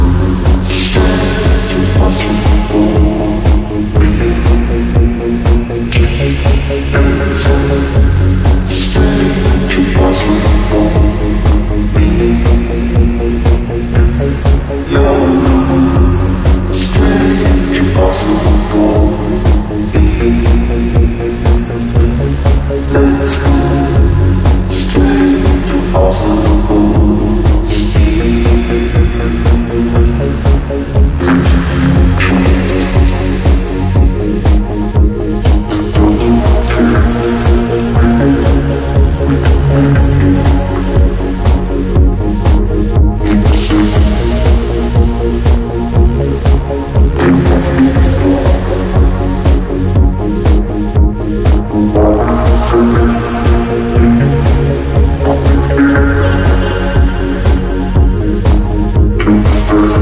Playing Drums At My Studio Sound Effects Free Download.
Playing drums at my studio sound effects free download